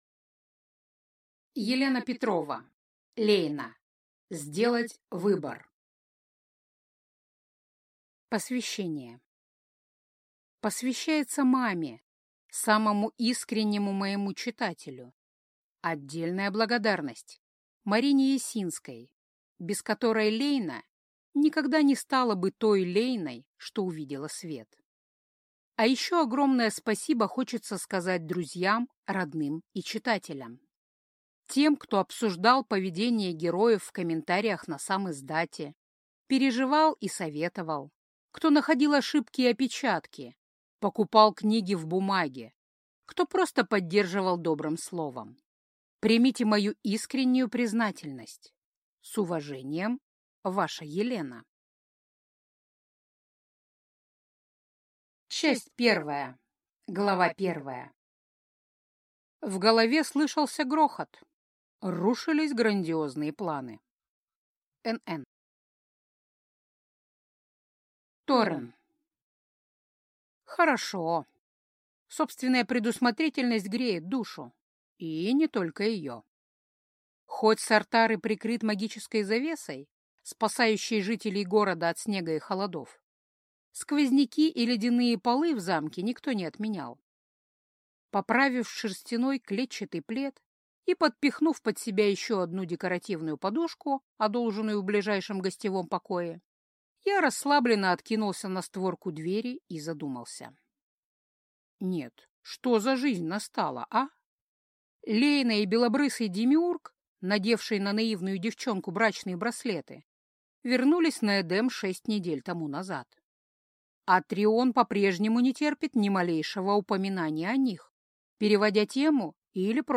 Аудиокнига Сделать выбор - купить, скачать и слушать онлайн | КнигоПоиск